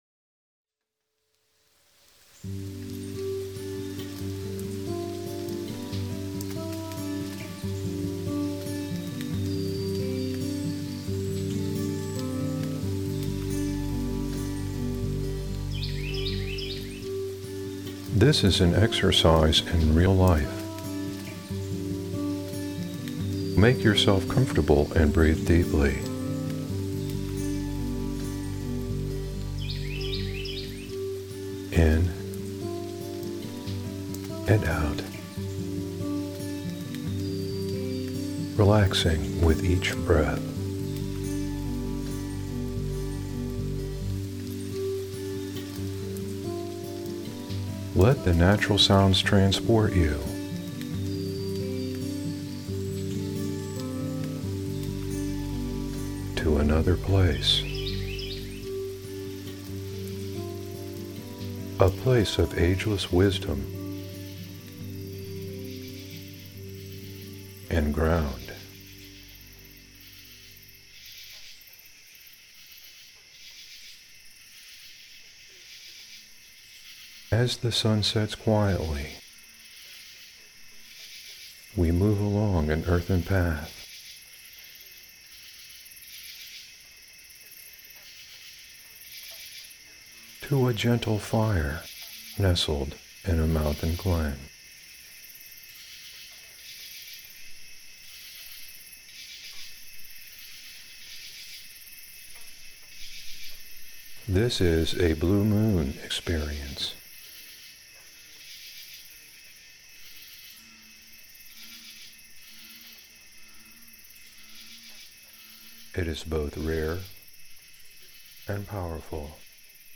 Sounds of nature at night transport you to a place of ageless wisdom.
Brief verbal guidance encourages you to relax and breathe in the fresh night air, exploring this realm in your mind and body. Connect with the earth, those elements you are made of, with this experience as rare and powerful as the blue moon itself.